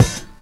Bass Cym.wav